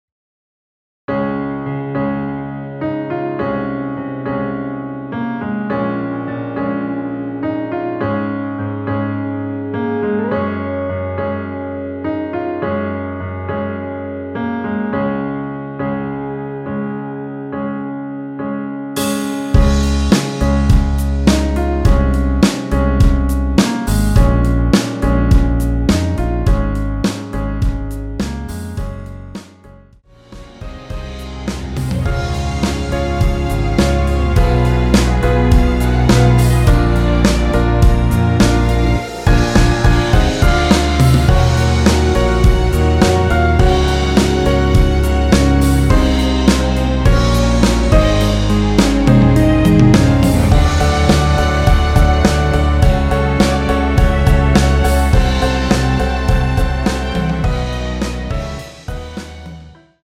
원키에서(-7)내린 MR입니다.
Db
앞부분30초, 뒷부분30초씩 편집해서 올려 드리고 있습니다.
중간에 음이 끈어지고 다시 나오는 이유는